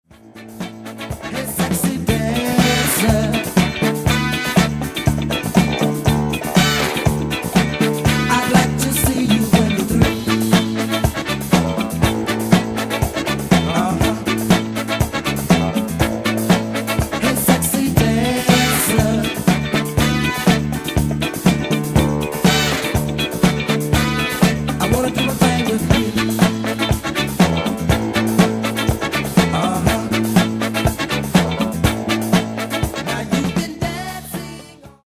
Genere:   Disco | Funky | Miami Sound